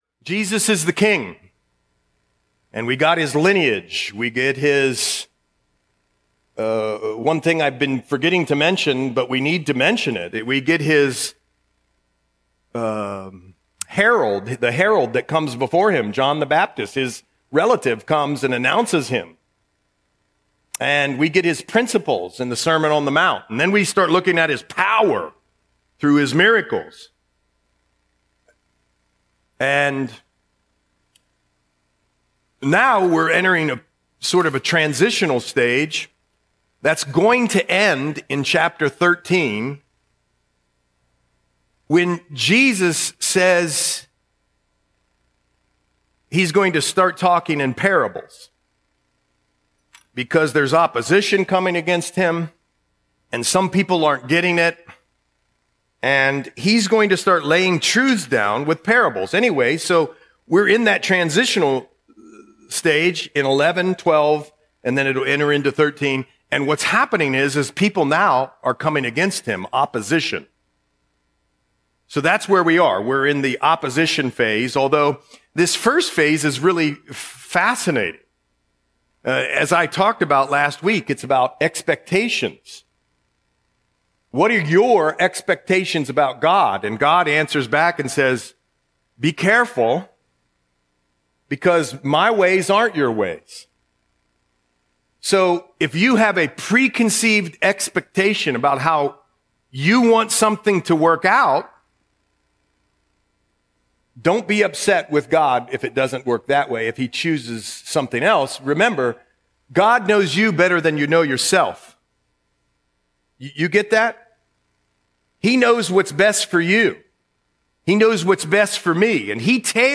Audio Sermon - February 15, 2026